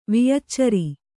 ♪ viyaccari